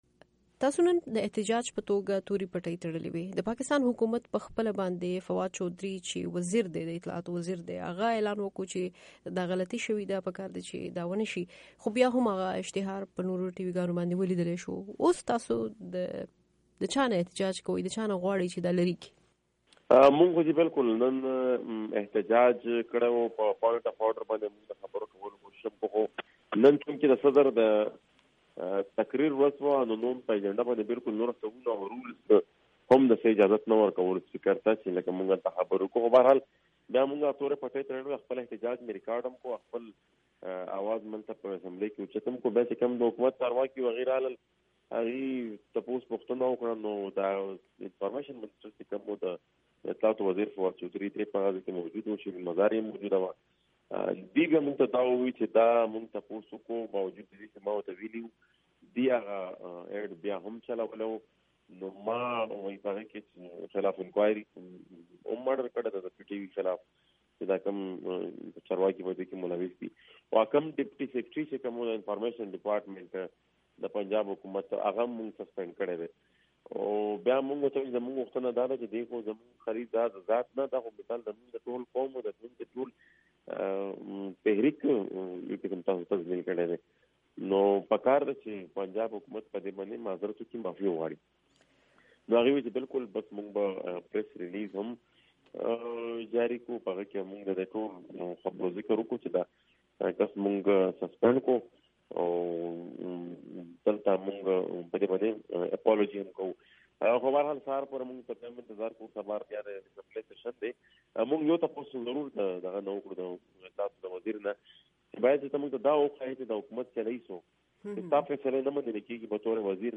د ښاغلي محسن داوړ سره مرکه